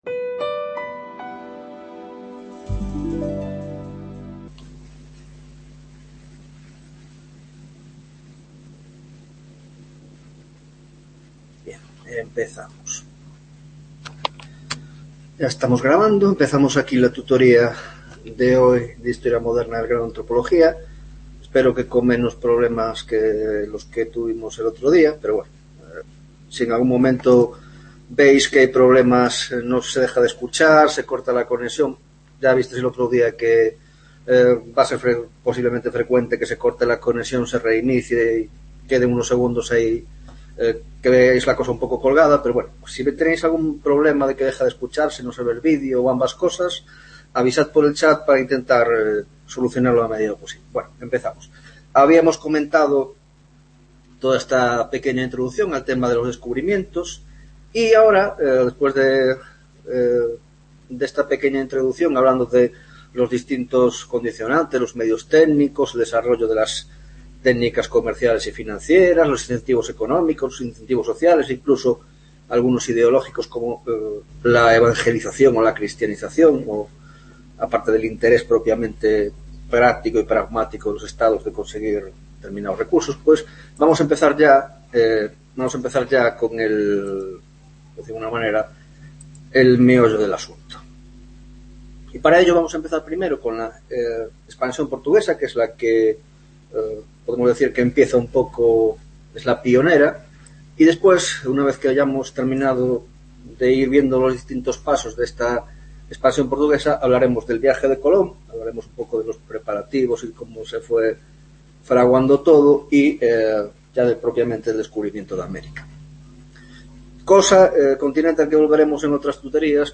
5ª Tutoria de Historia Moderna, Grado de Antropología - Descubrimientos y espansión portuguesa y castellana (2ª parte)